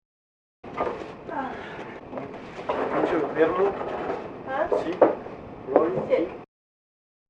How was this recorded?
mucho enfermo? 7 sec. mono 74k